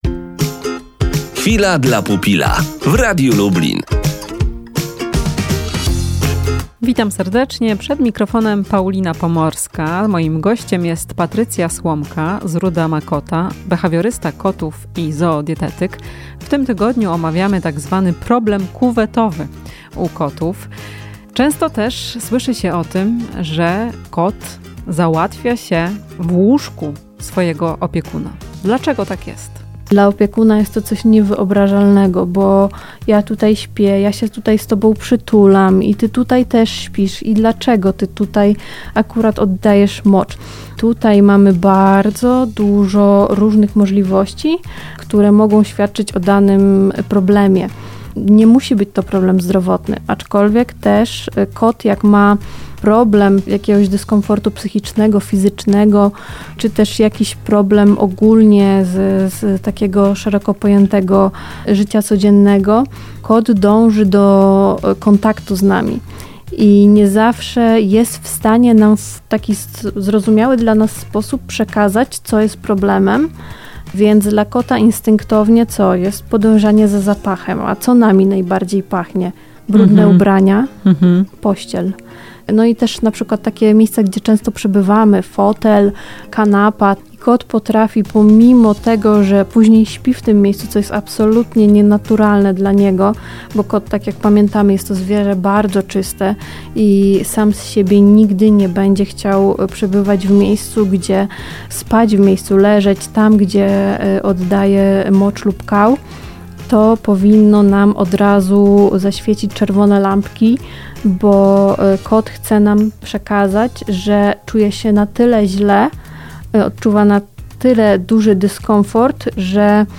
Rozmowa z behawiorystą kotów i zoodietykiem